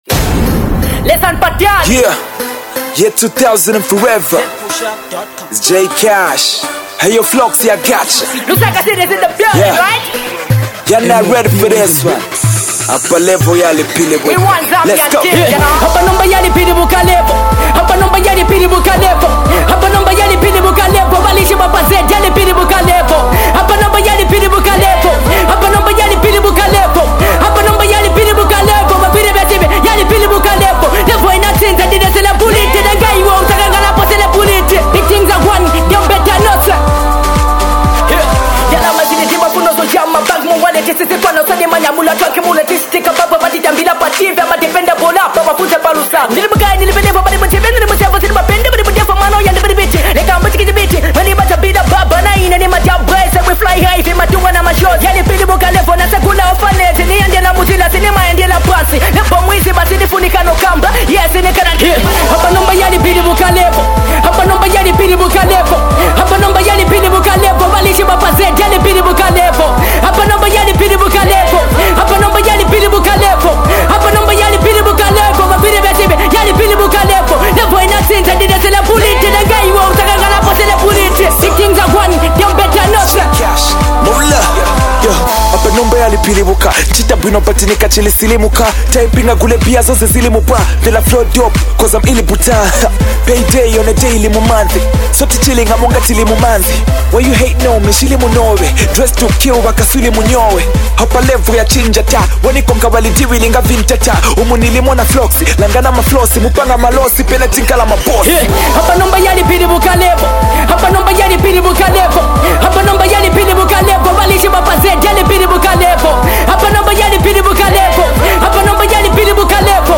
hiphop joint